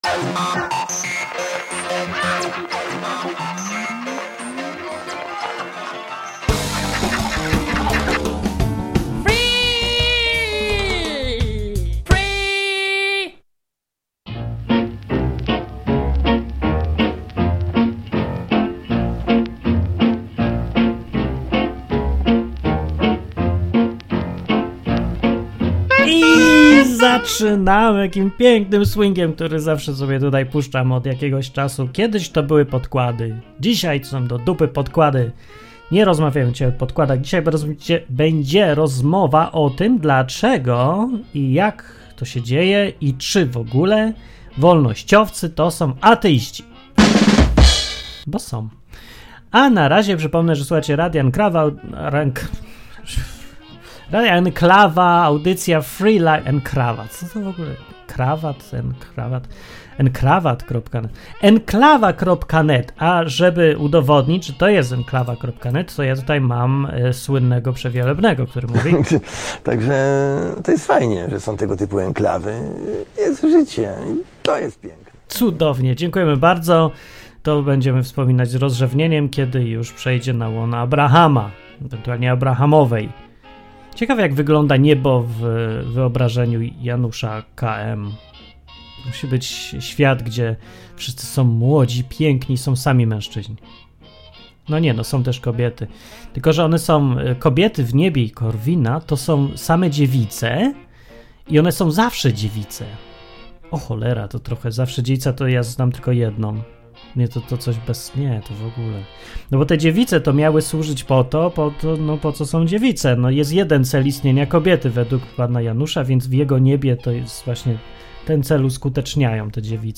Program dla wszystkich, którzy lubią luźne, dzikie, improwizowane audycje na żywo.